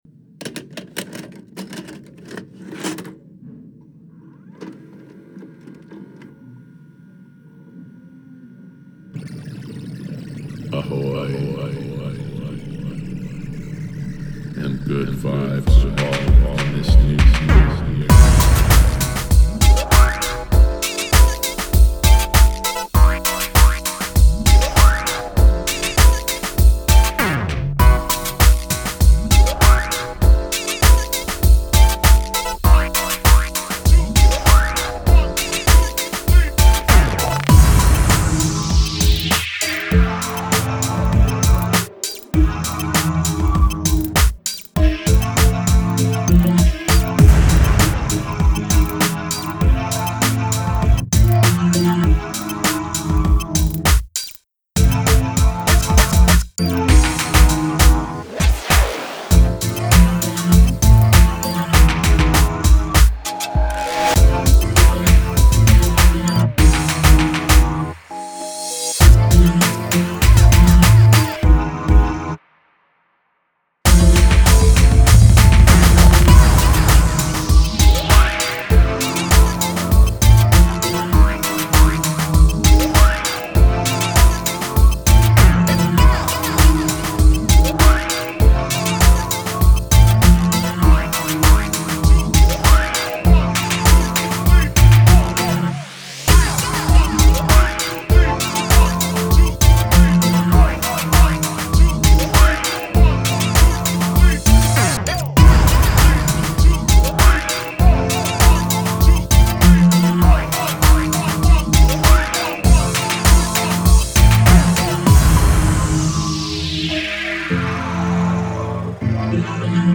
BPM99
Audio QualityPerfect (High Quality)
04/09/2021 - Audio remastered.